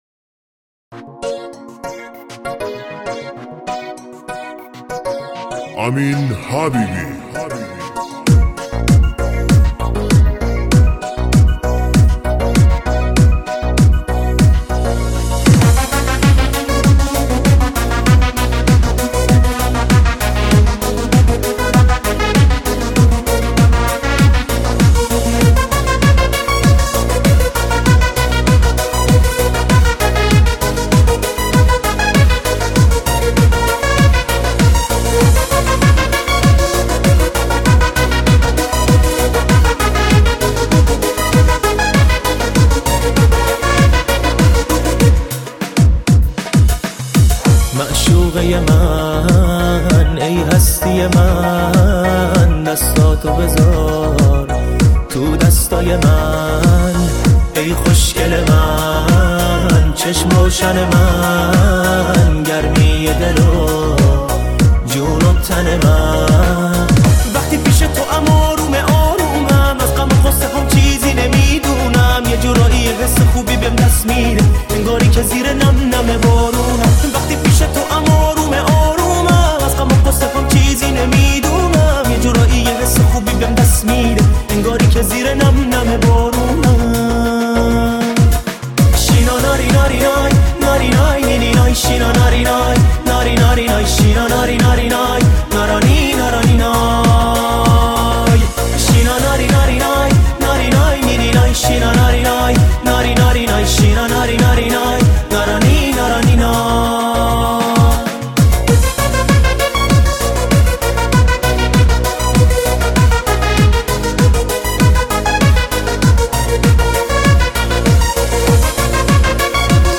پاپ عاشقانه